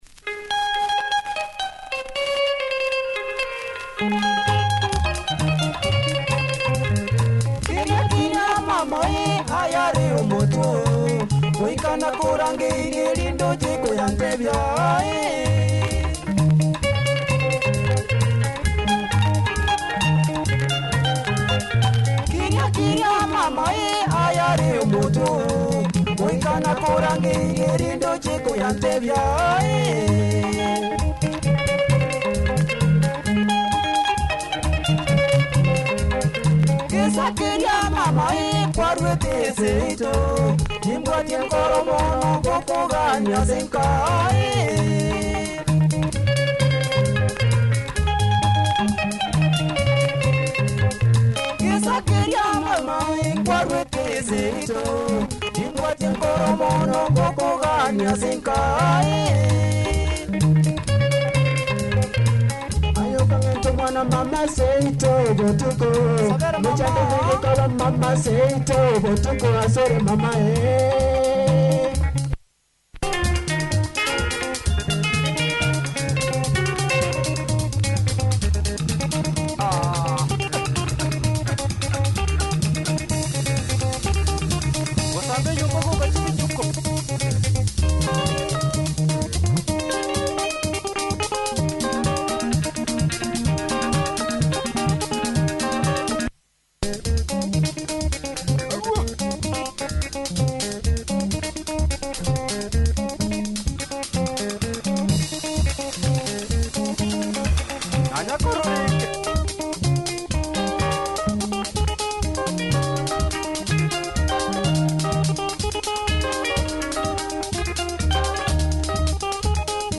Good drive and punch